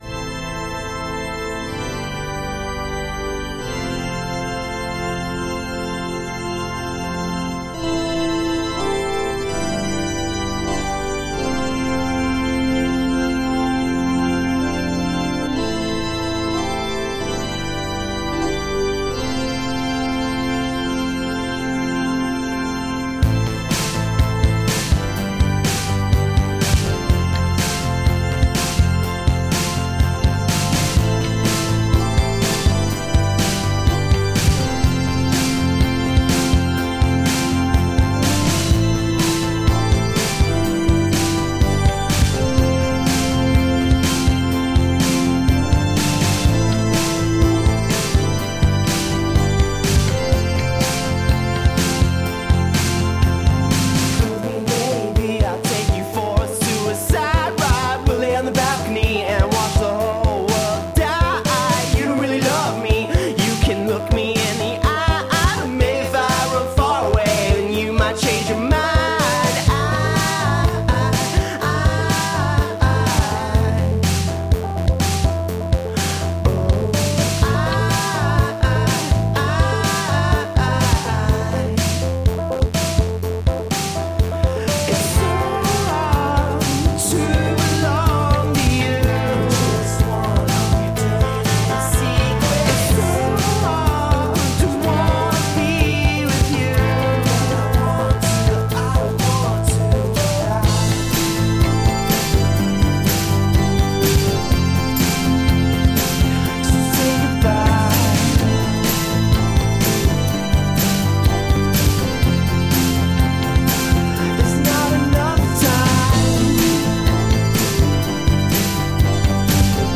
Sounds like synth pop walking down a dark alley.
Tagged as: Alt Rock, Rock, Prog Rock